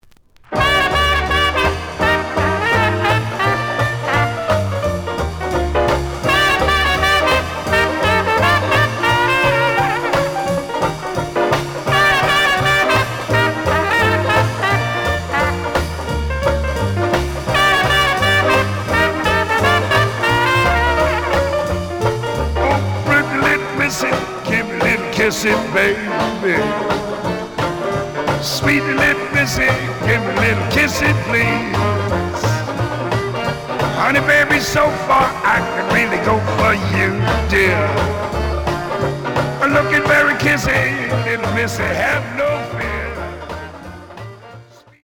The audio sample is recorded from the actual item.
●Format: 7 inch
●Genre: Vocal Jazz